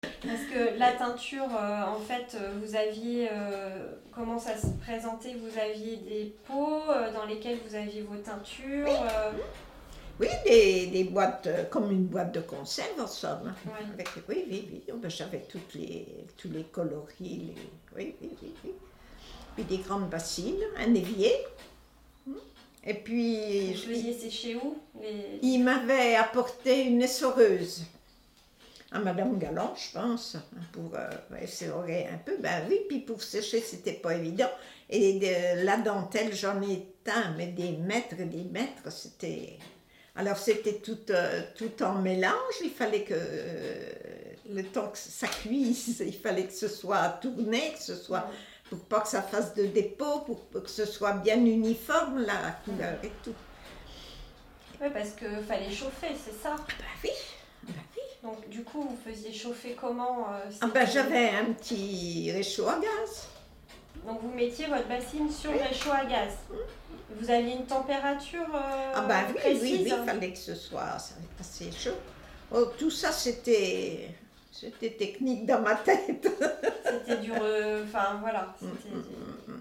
Figure 14 : Extrait vidéo du témoignage d’une ouvrière (thermocolleuse) en confection.